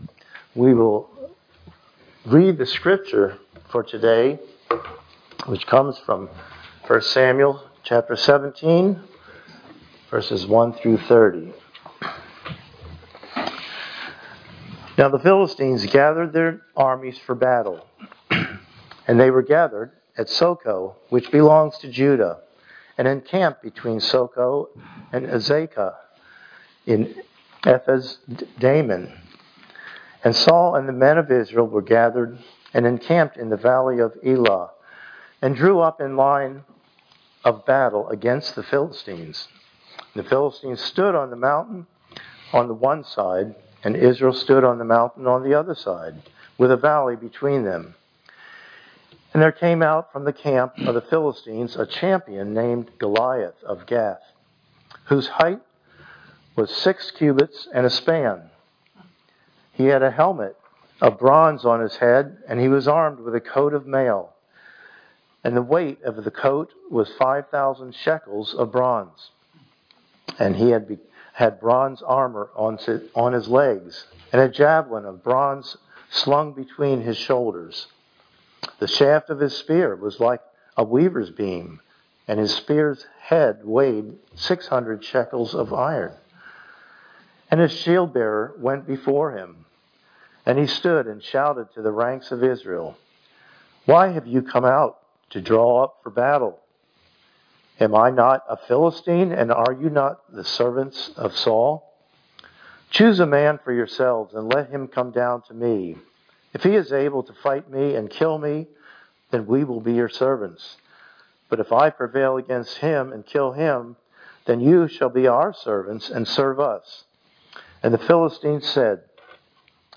Passage: 1 Samuel 17:1-30 Service Type: Sunday Morning Worship